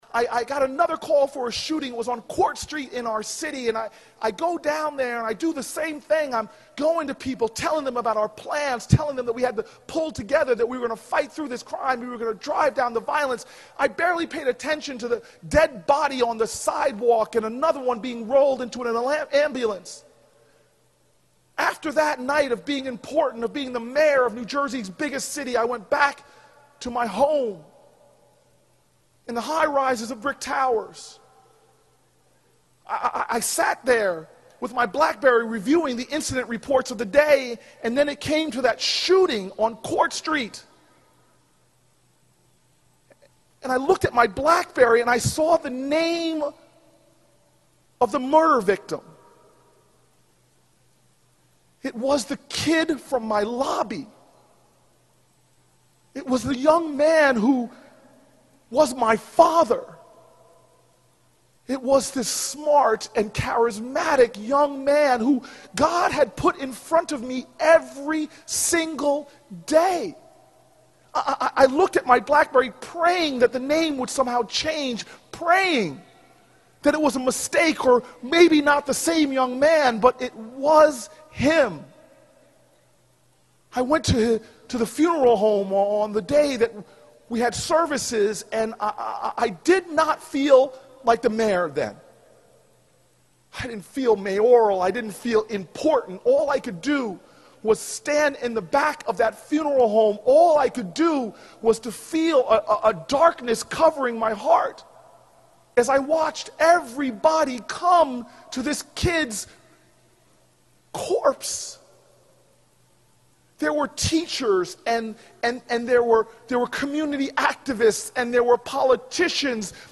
公众人物毕业演讲 第448期:科里布克2013年耶鲁大学(16) 听力文件下载—在线英语听力室